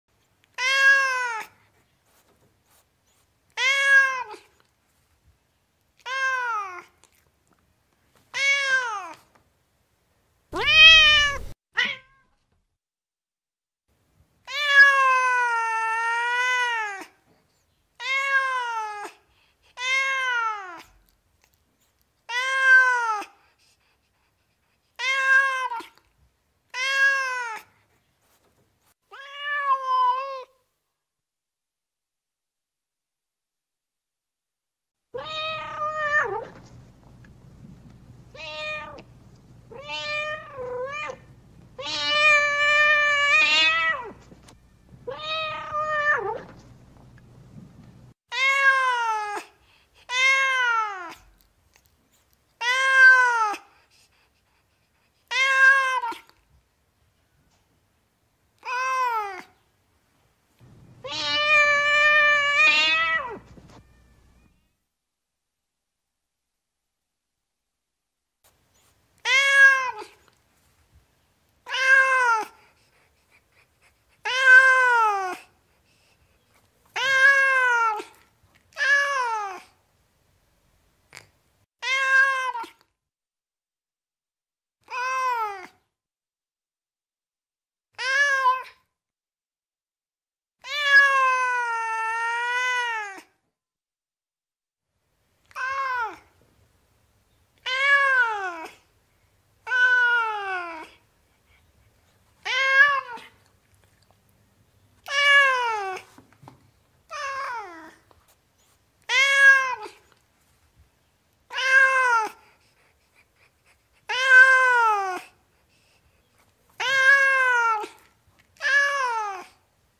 Cat Meowing Sound Effect Free Download
Cat Meowing